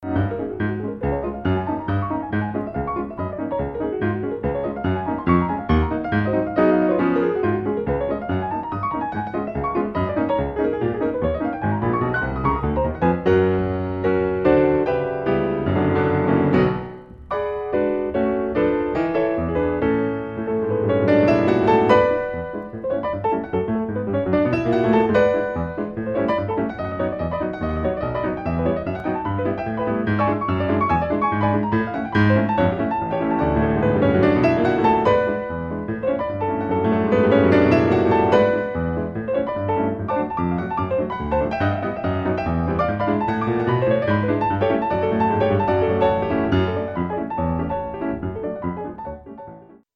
en concert
piano
trompette